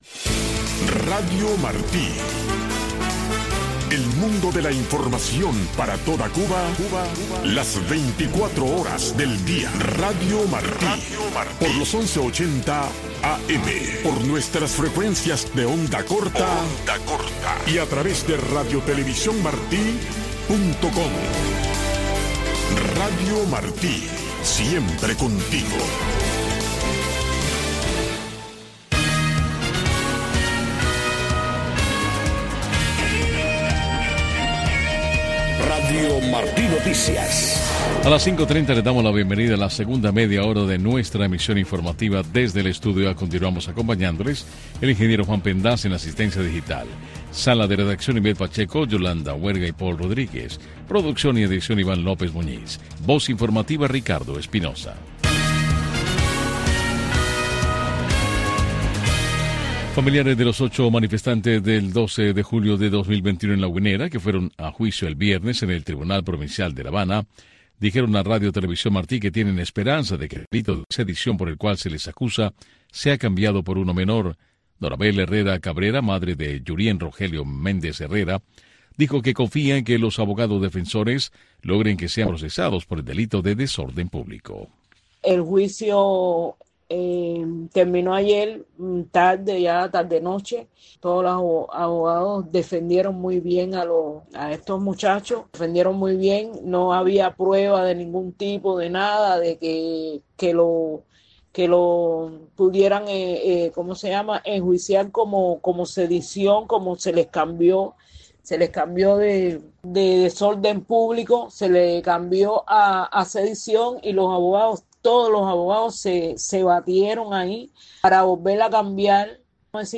Noticiero Radio Martí presenta los hechos que hacen noticia en Cuba y el mundo